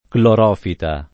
clorofita [ klor 0 fita ]